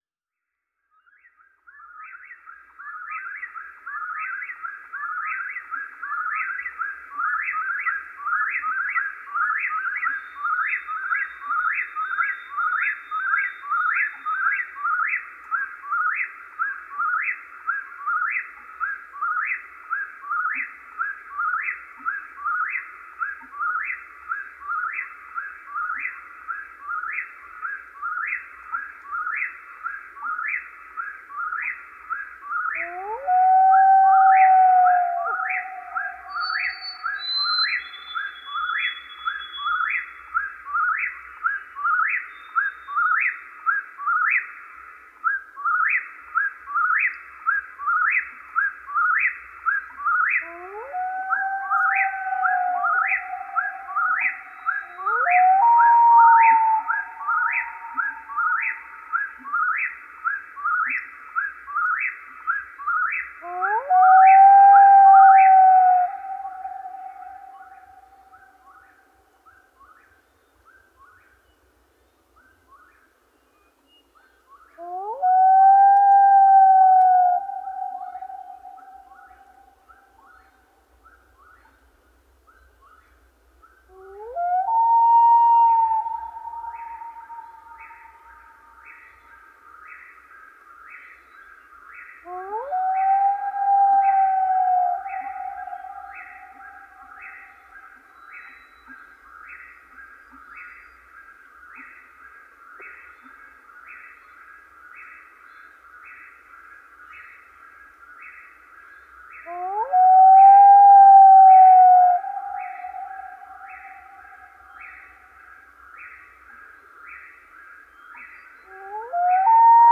LeCountdown/QP01 0096 Wetland lake early morning.wav at db026a68118ae2eec591058ad847e6dcb99b4e77 - LeCountdown - Gitea: Git with a cup of tea